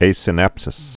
(āsĭ-năpsĭs)